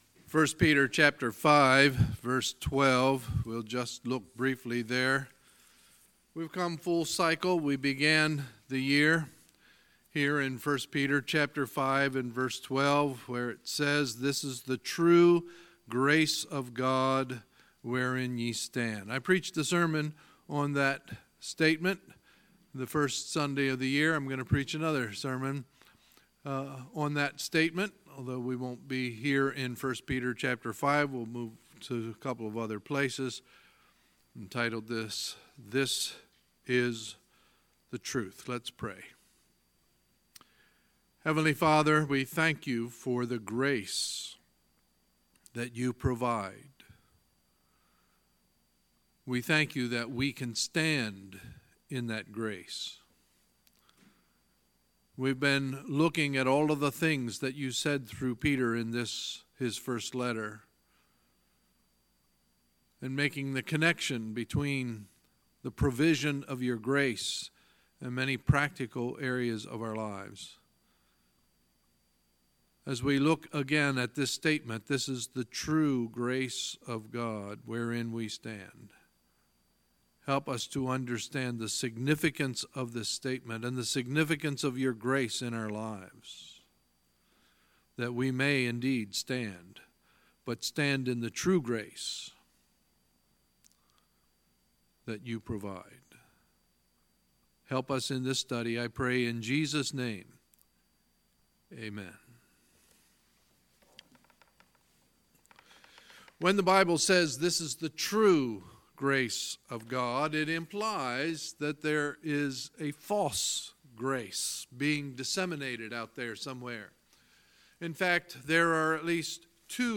Sunday, August 26, 2018 – Sunday Morning Service